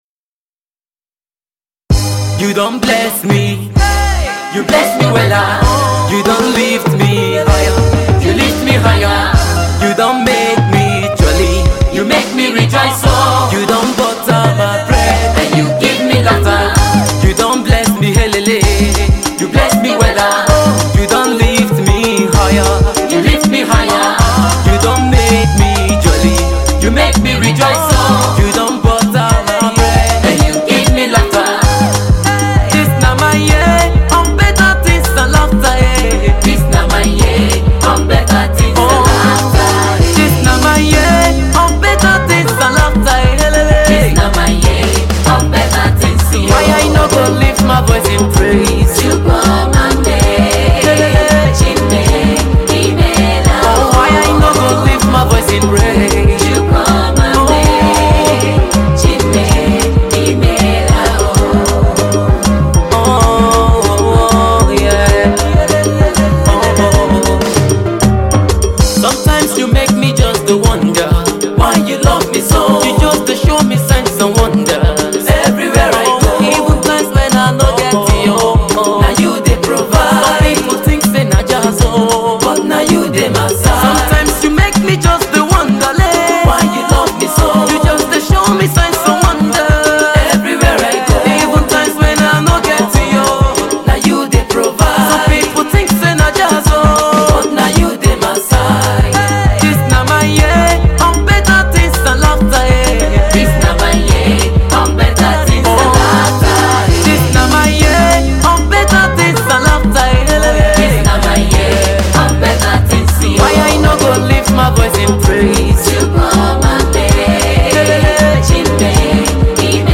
Tags: Gospel Music,